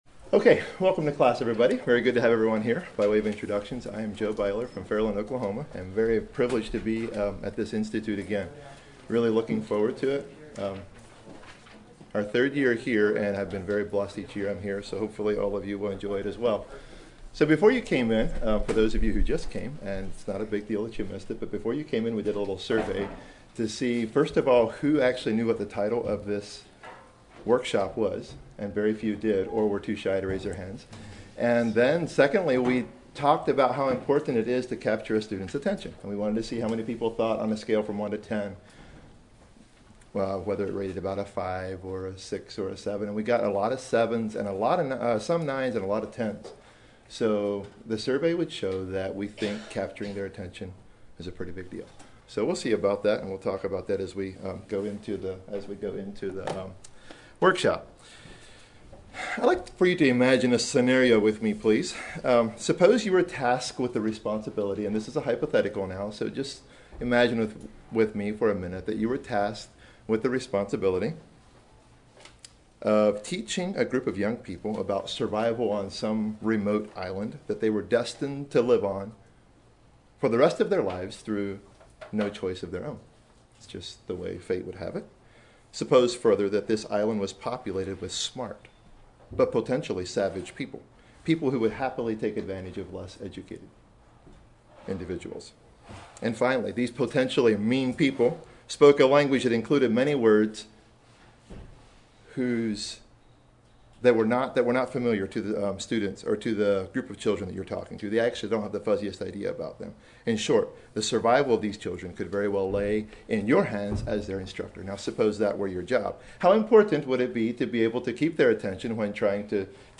Home » Lectures » Capturing Their Attention